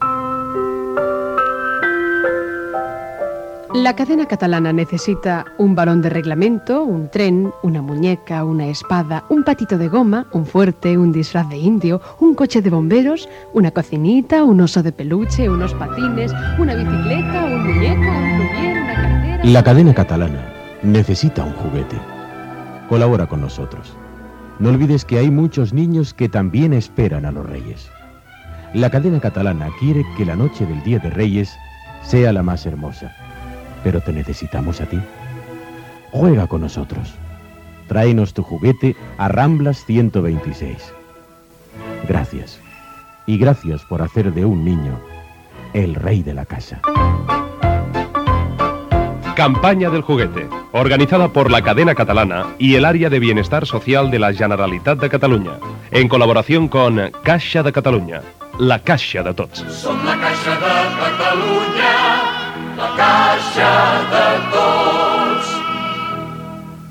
Promoció de la campanya de recollida de joguines de l'emissora "Campaña del juguete" i publicitat.